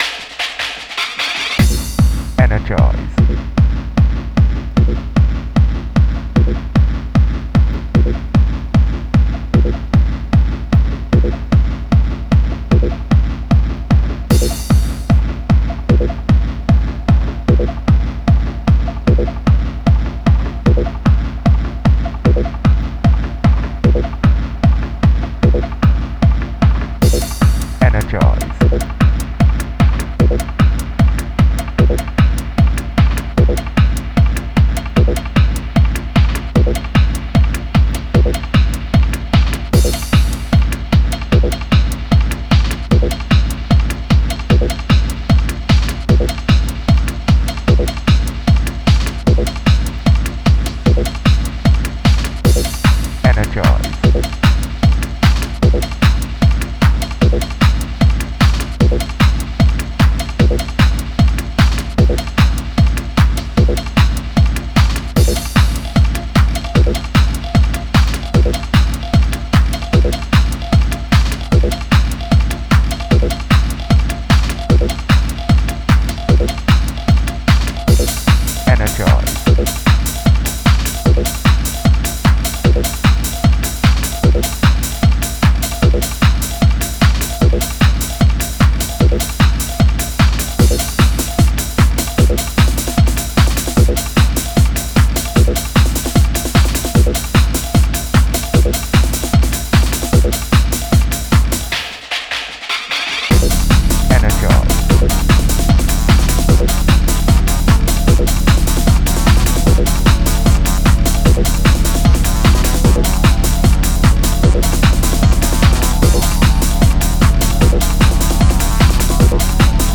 Acid Techno
acid techno driver
squelchy 303s, slamming breakbeats and intricate percussion